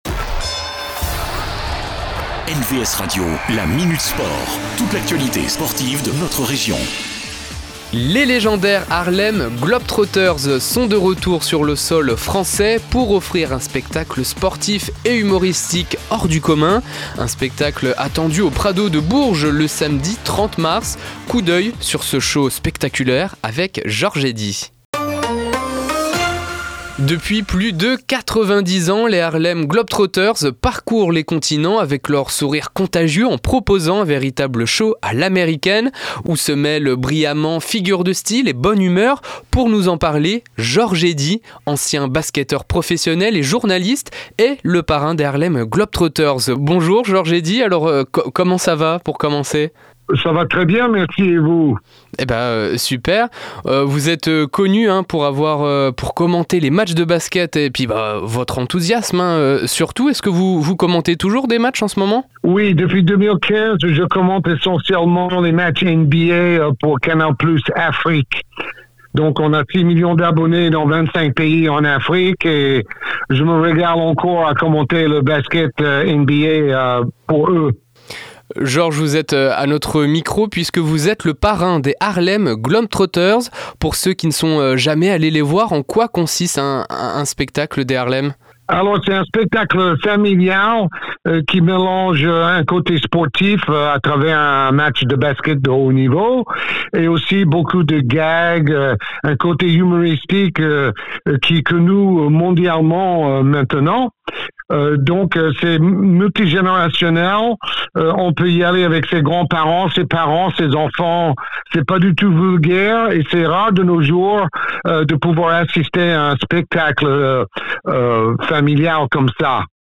Cette semaine : George Eddy, commentateur franco-américain de basket et parrain de la tournée des Harlem Globetrotters.